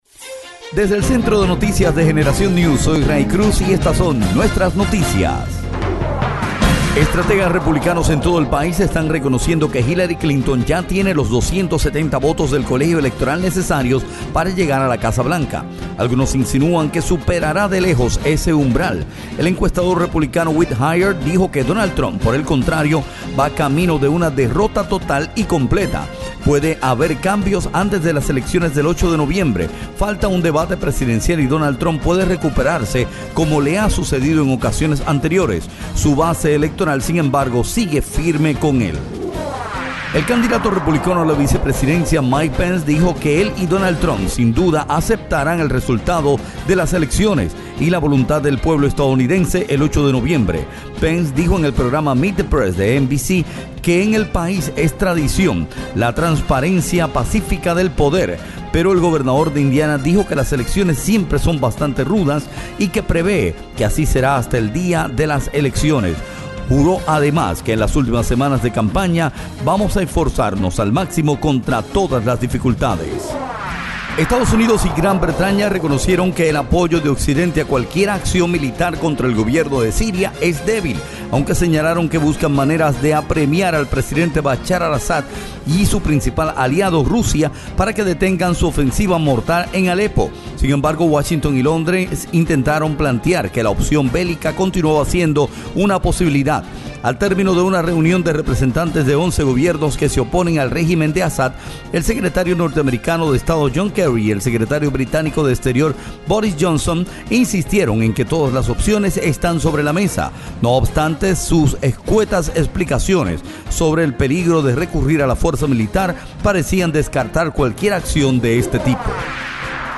Resumen de Noticias 17 oct